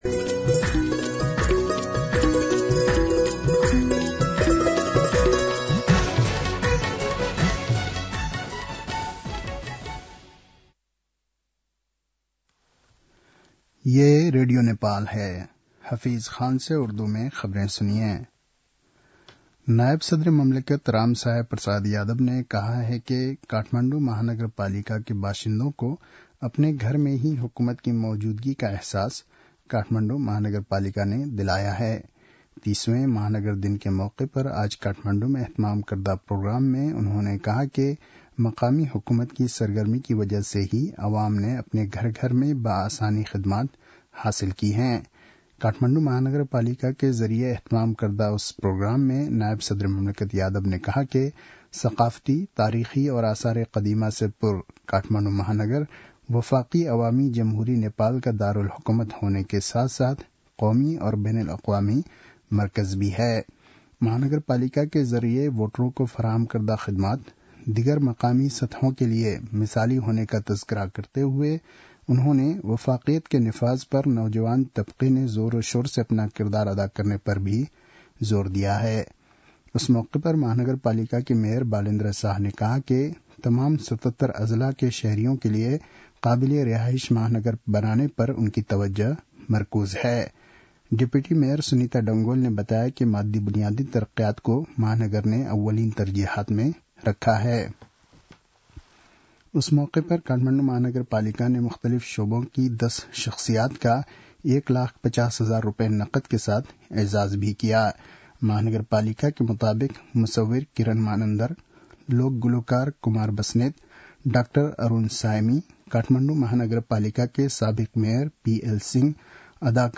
उर्दु भाषामा समाचार : ३० मंसिर , २०८१
Urdu-News-8-29.mp3